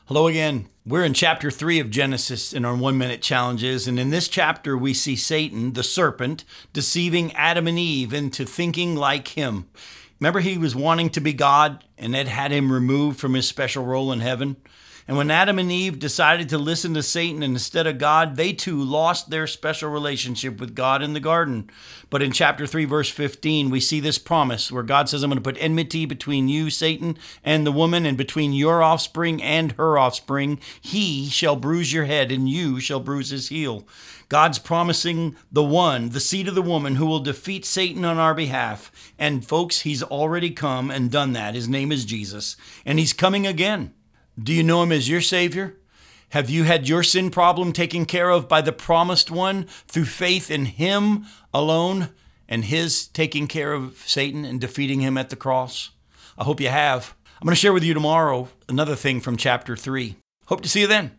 five-minute weekday radio program aired on WCIF 106.3 FM in Melbourne, Florida